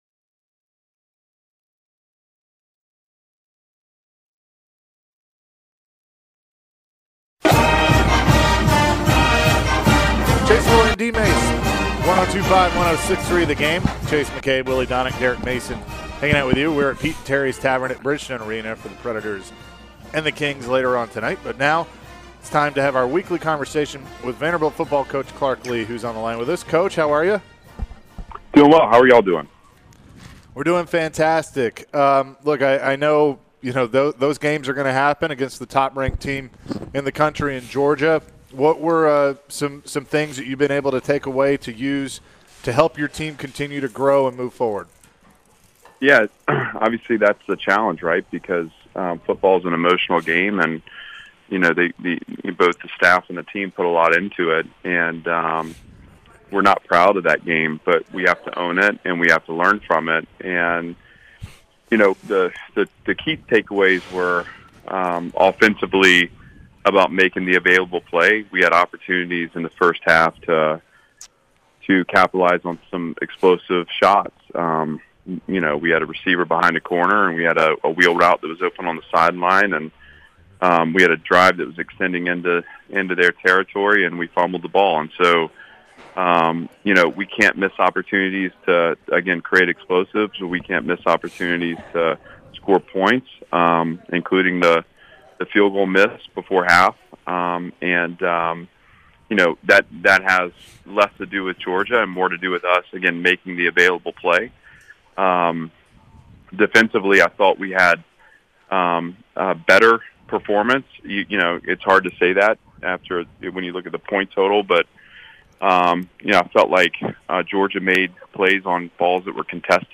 Clark Lea Full Interview (10-18-22)